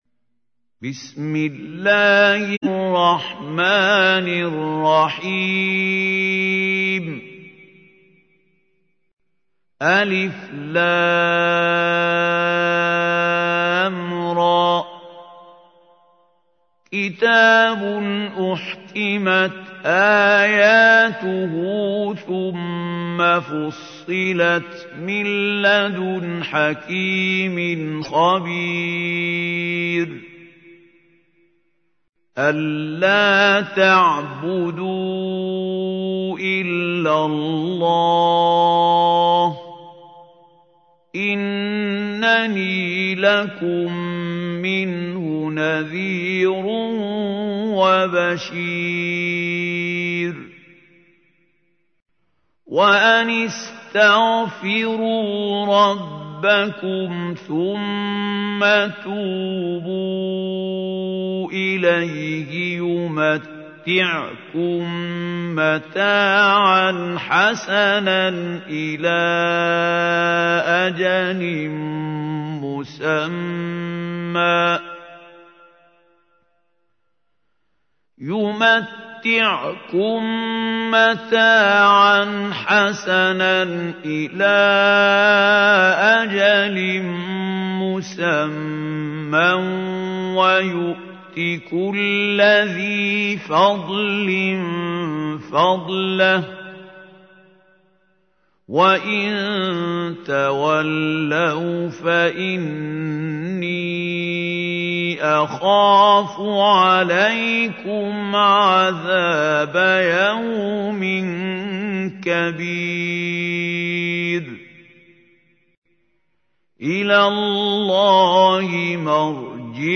تحميل : 11. سورة هود / القارئ محمود خليل الحصري / القرآن الكريم / موقع يا حسين